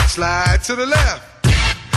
slide_left.mp3